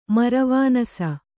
♪ maravānasa